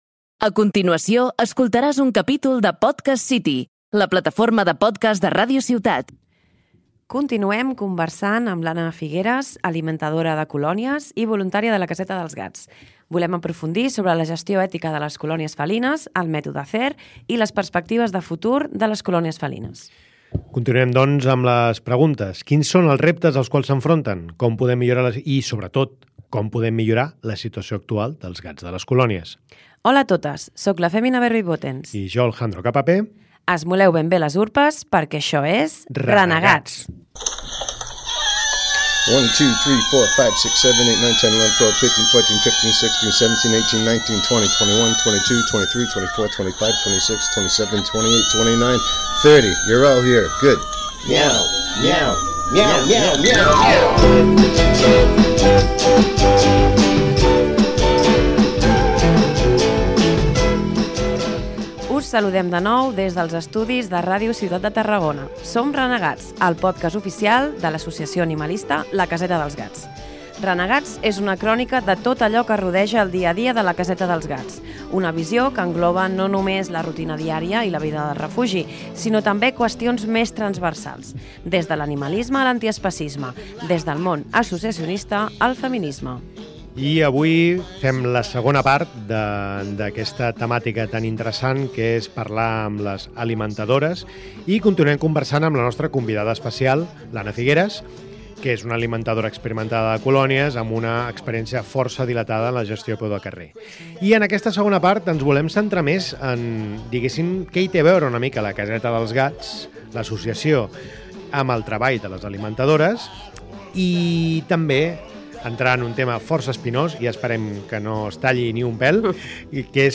Renegats 2-4 | Gestió de Colònies Felines - Parlem amb les alimentadores (II) - PodcastCity